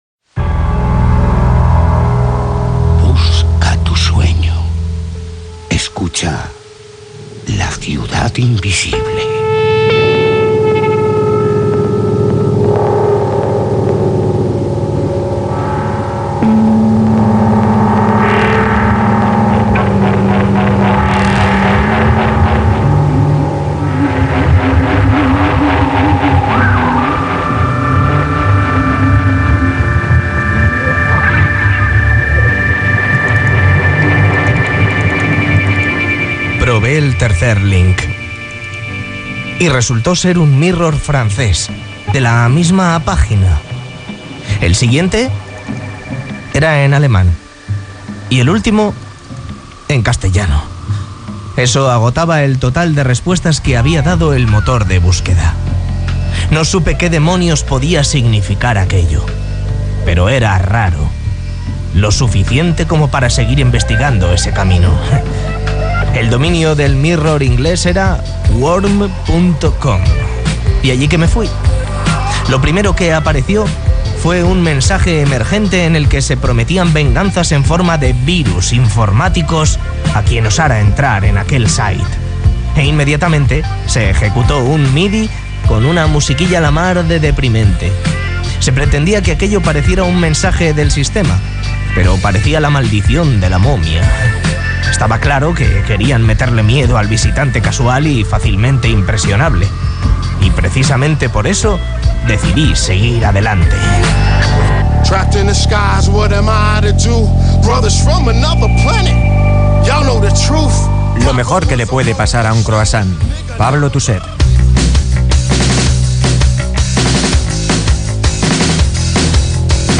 Careta del programa, lectura d'una narració de Pablo Tusset, tema musical, entrevista als integrants del grup musical barceloní Virus